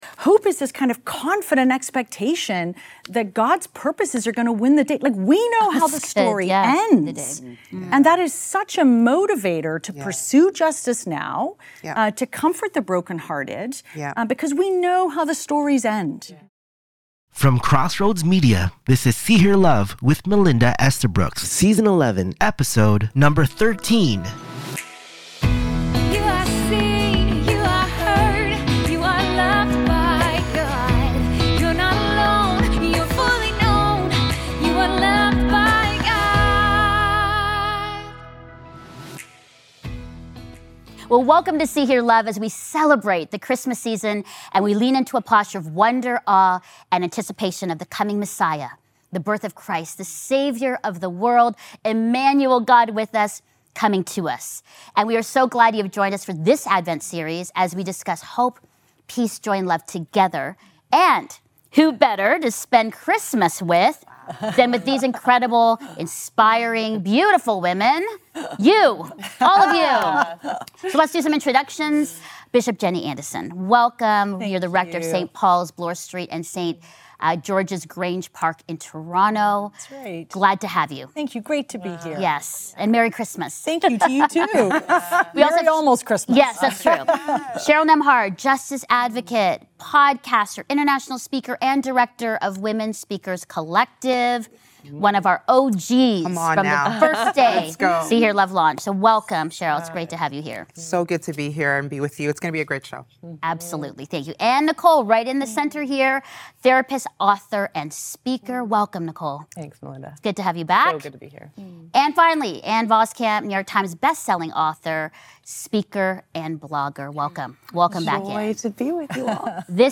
This honest, warm, spirit-filled conversation invites you to breathe, rest, and receive hope again this Christmas.